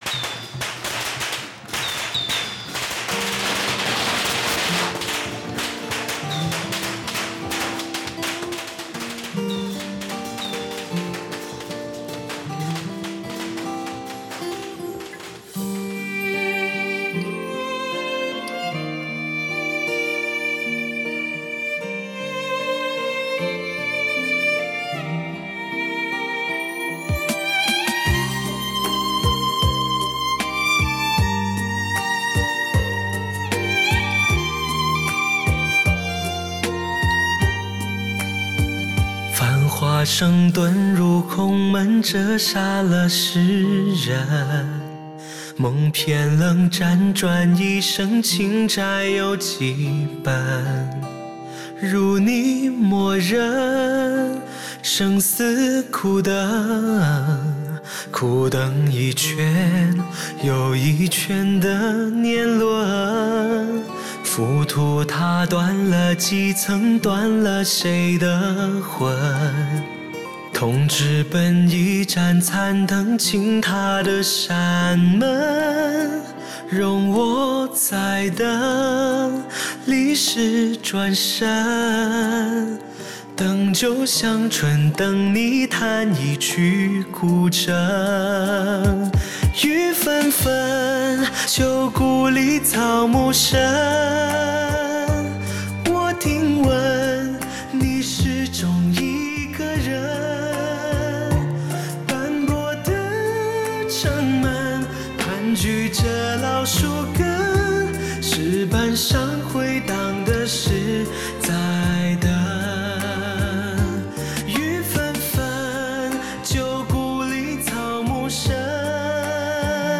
他那温润优雅的嗓音，气量充沛，音域广阔，纯熟温润的歌声。